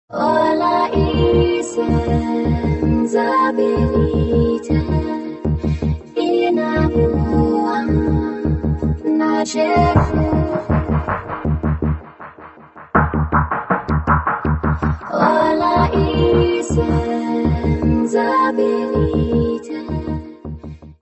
world ambient
voz
keyboards
bateria, percussão.
Área:  Pop / Rock